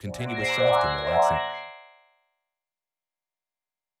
Each copy gets a different delay and a different filter setting so that if you put a short sound in (for example) you hear the 19 filters in order of the delay times. In the example here, the delay times are just 17, 34, 51, ... (the multiples of 17) - of course, the number 17 is just a setting that you can change later. The filters are tuned to a whole-time scale, specifically, the MIDI pitches 72, 74, 76, ...,